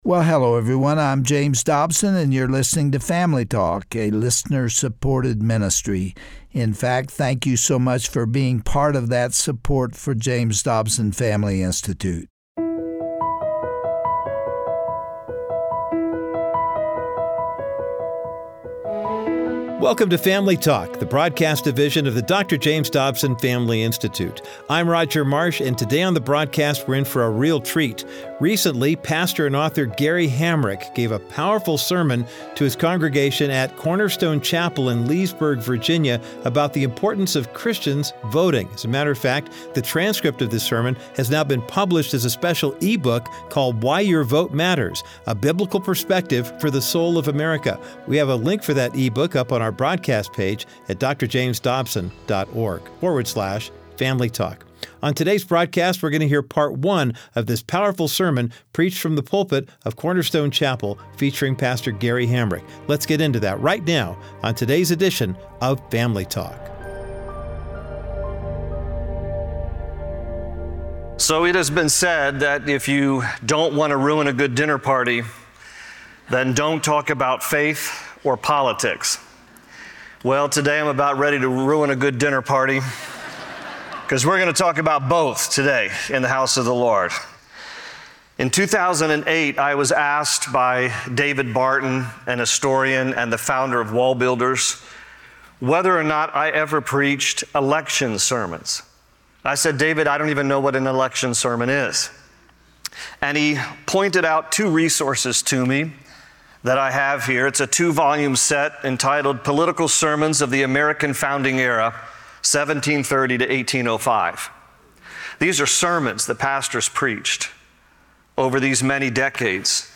If you have never heard the term, “election sermon,” most likely, you are not alone.